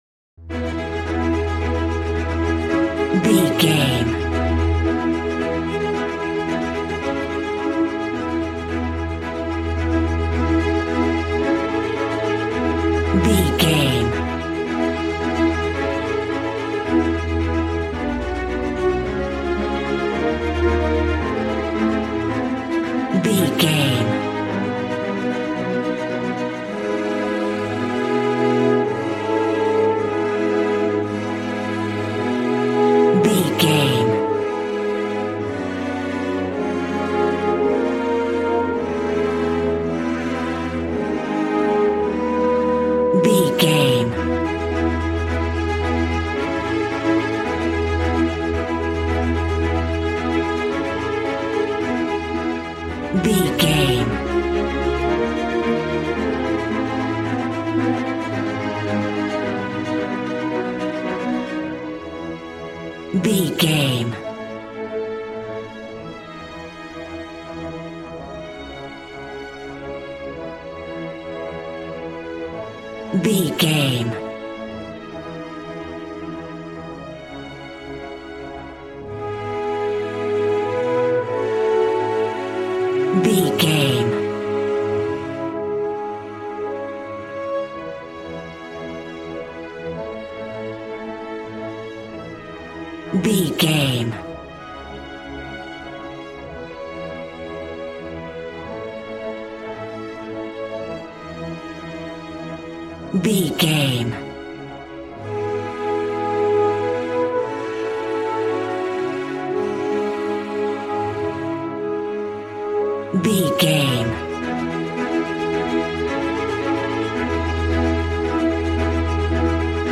Regal and romantic, a classy piece of classical music.
Ionian/Major
regal
cello
violin
strings